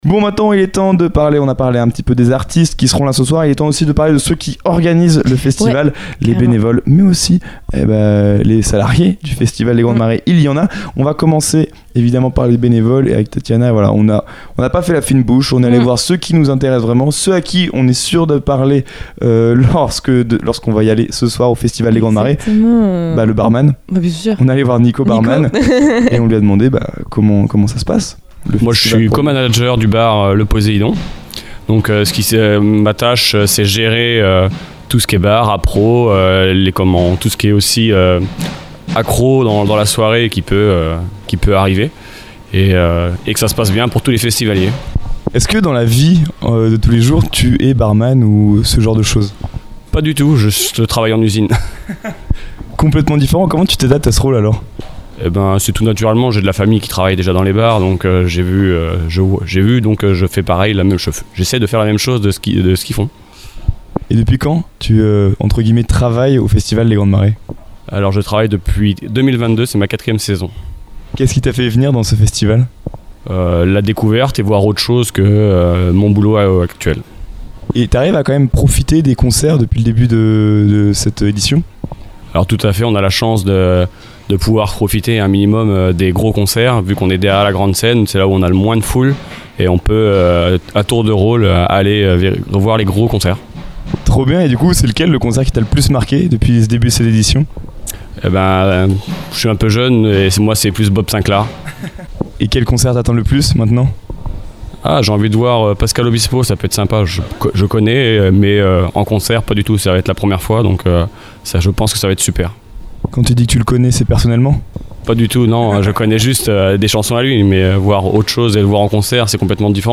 Une interview authentique et chaleureuse qui met en lumière l’engagement des bénévoles et l’ambiance collective qui fait battre le cœur des Grandes Marées.